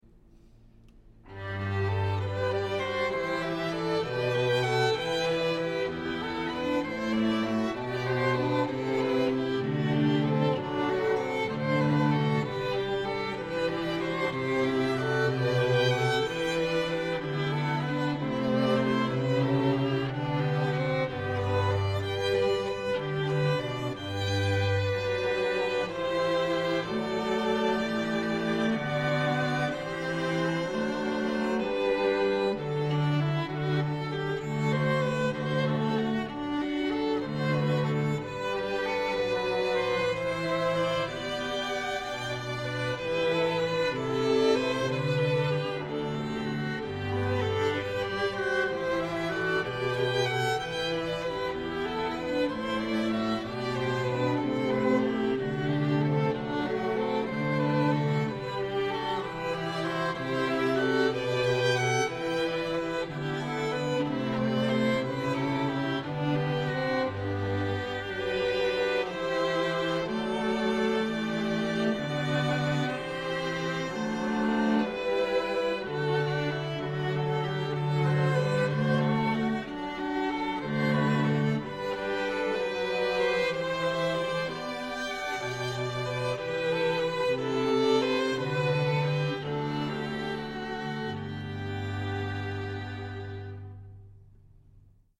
Elysium String Quartet – performing throughout the Lehigh Valley, Delaware Valley, and Philadelphia, PA